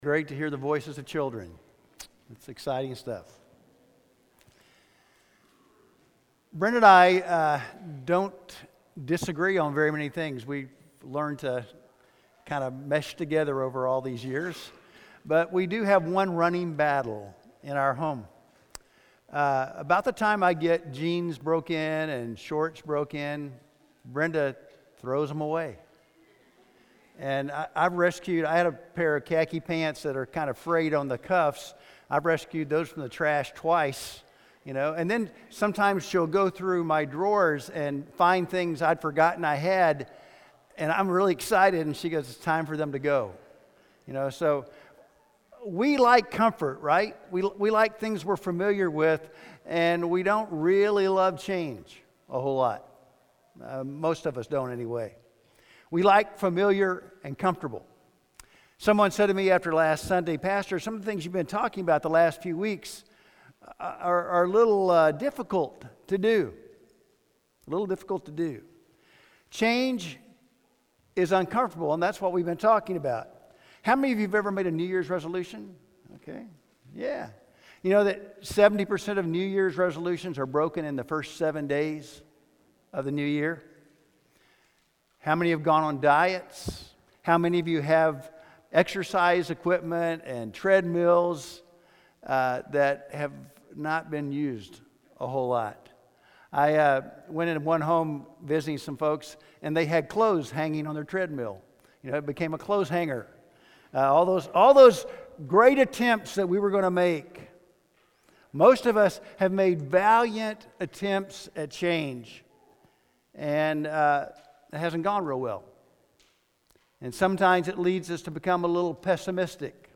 Making Changes – The Transformation Choice. Sermon